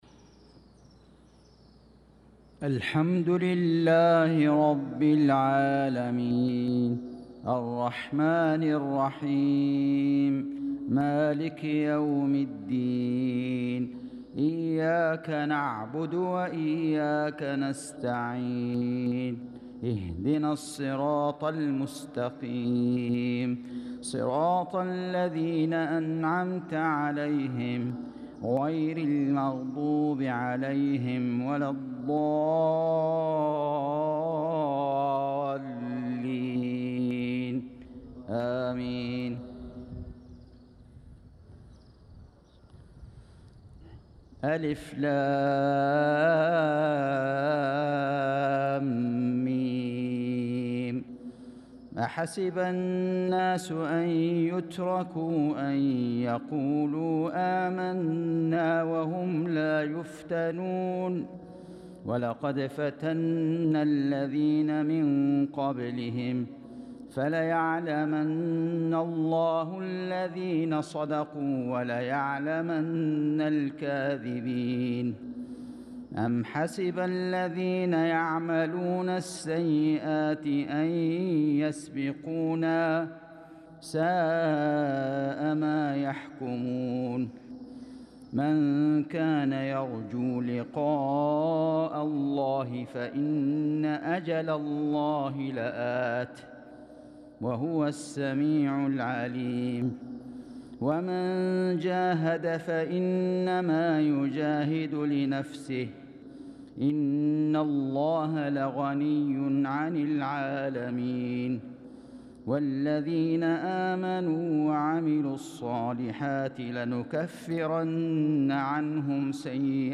صلاة المغرب للقارئ فيصل غزاوي 23 ذو القعدة 1445 هـ
تِلَاوَات الْحَرَمَيْن .